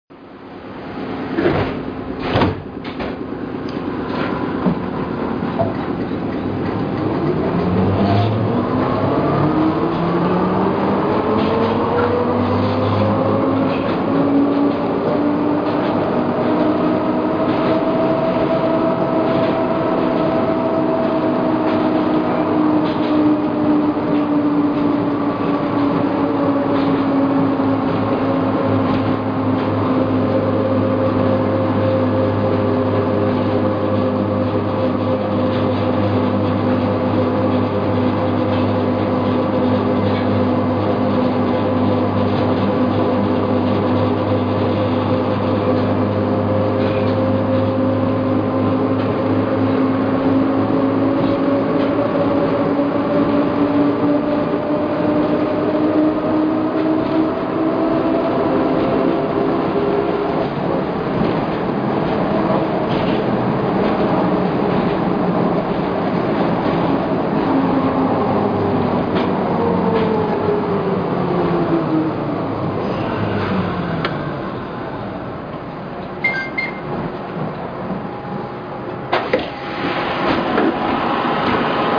・MRM100形走行音
【ﾓﾝｷｰﾊﾟｰｸﾓﾉﾚｰﾙ線】犬山遊園〜成田山（1分22秒：642KB）
モノレールとは思えないにぎやかな走行音。古さを感じます。